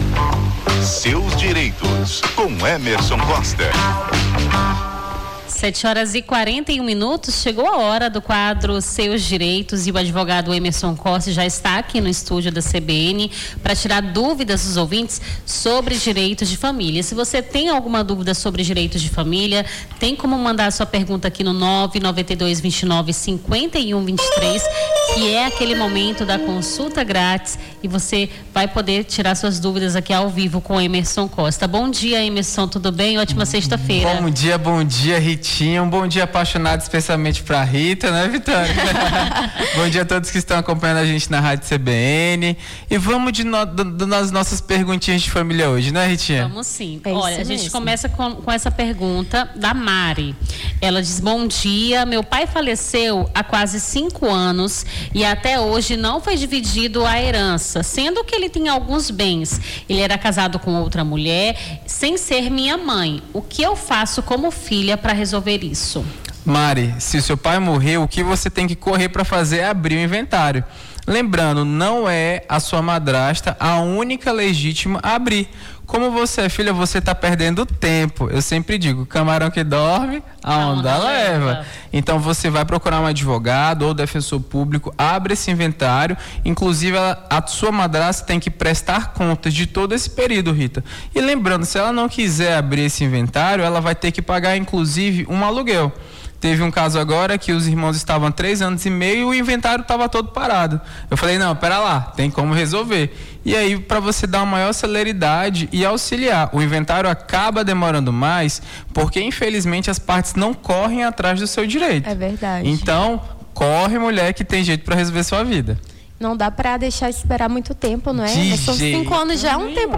Seus direitos: advogado tira-dúvidas sobre direito de família